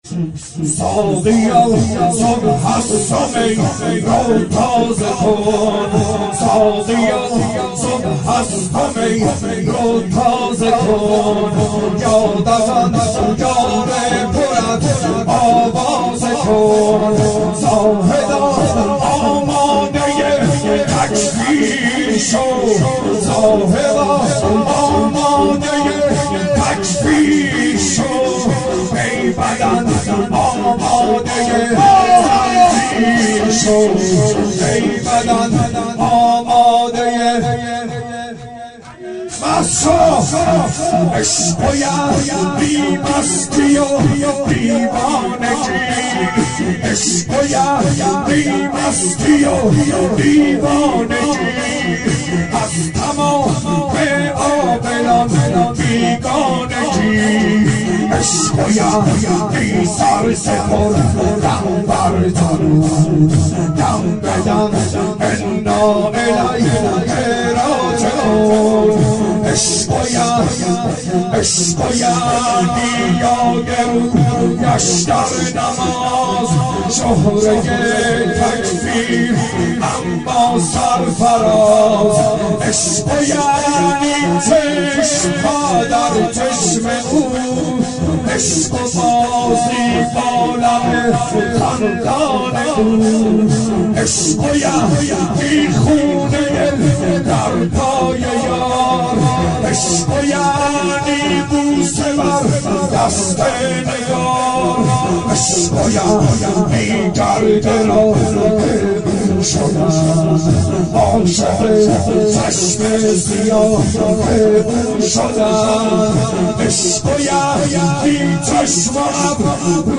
شب شهادت امام هادی علیه السلام 92 محفل شیفتگان حضرت رقیه سلام الله علیها